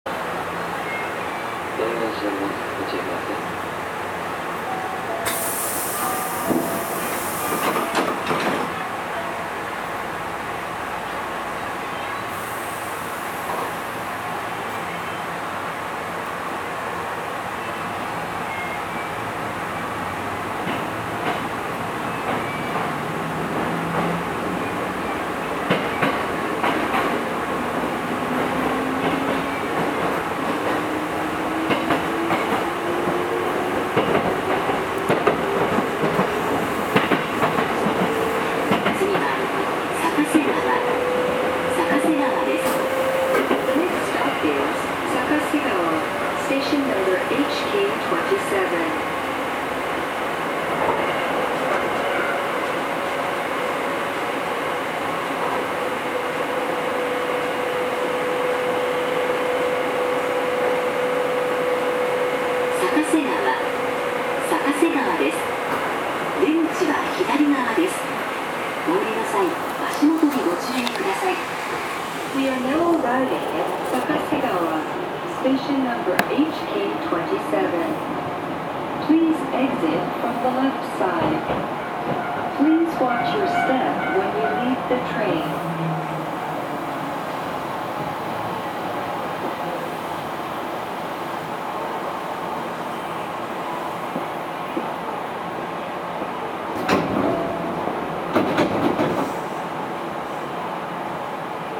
走行機器は抵抗制御で定格170kWのSE-542形直流直巻モーターを搭載しています。
駆動方式はWN駆動方式で、最高速度は110km/hです。
走行音
録音区間：宝塚南口～逆瀬川(お持ち帰り)